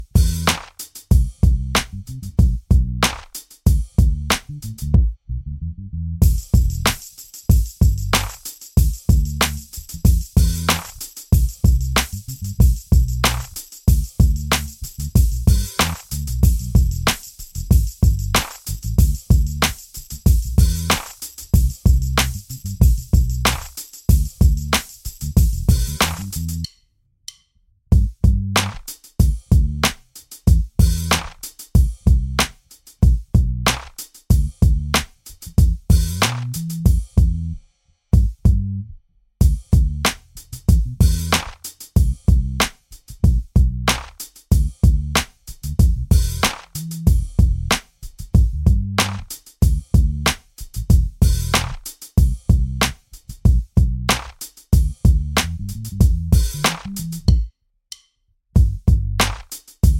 Minus Main Guitar For Guitarists 3:08 Buy £1.50